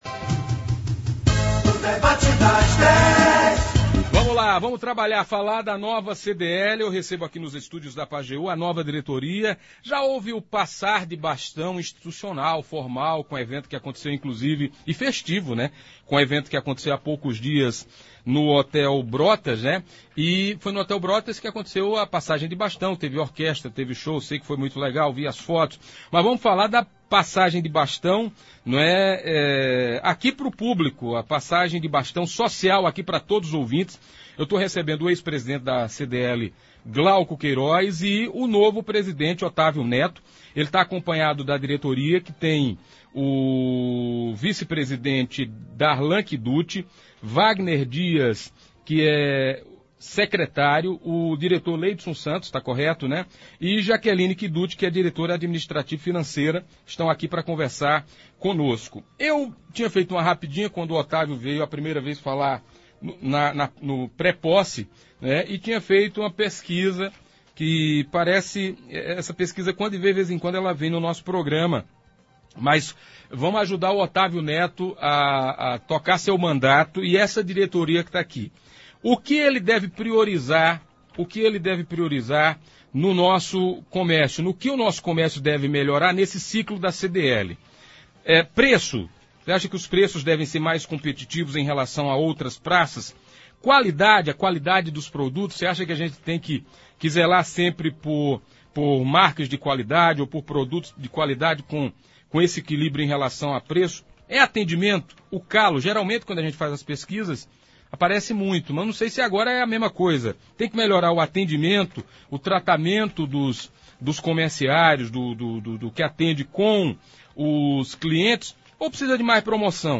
ouça abaixo a íntegra do debate de hoje.